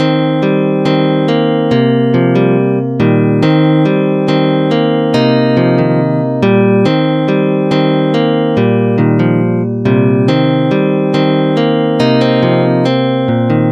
悲伤的合成器
标签： 140 bpm Trap Loops Synth Loops 2.31 MB wav Key : Unknown
声道立体声